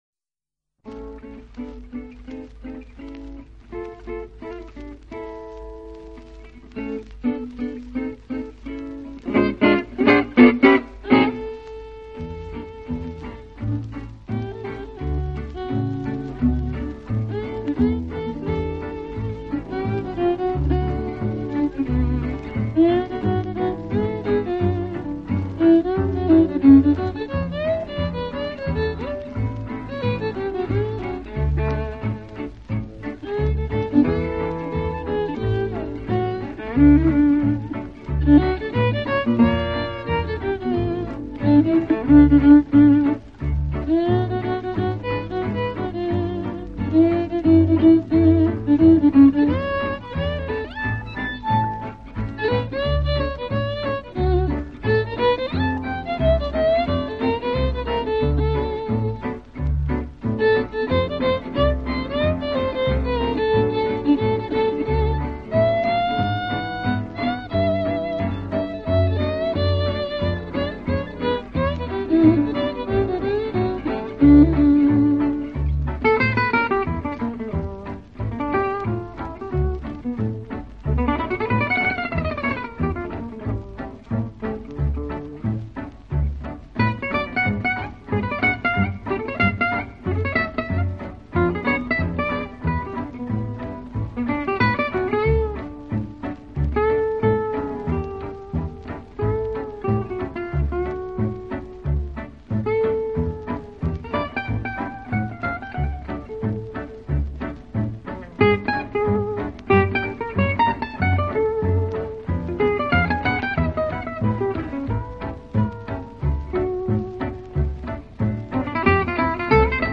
音乐类型：Jazz
活泼的跳弓、写意的揉弦、无懈可击的音准，让人 难以相信这是出自一位八旬老人之手，小提琴音色温暖醇厚，松香味儿十足。